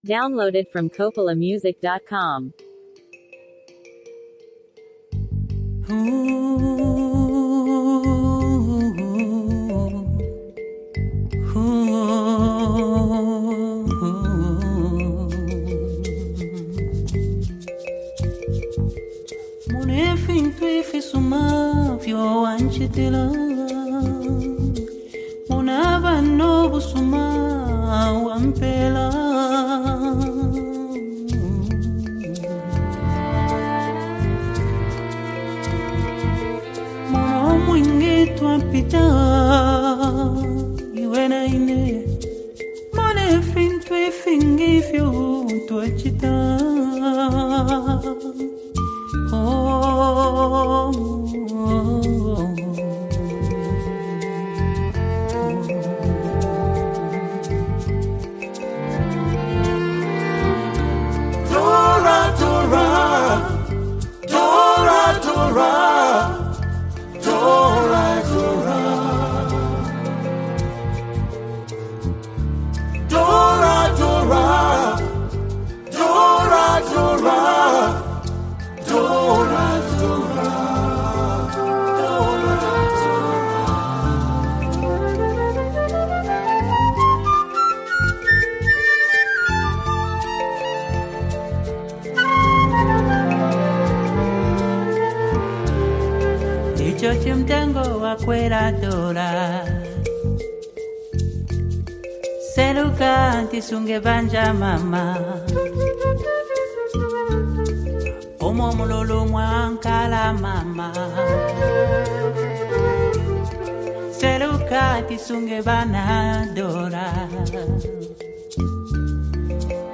is a deeply emotional and soulful song
wrapped in beautiful harmonies and traditional influences.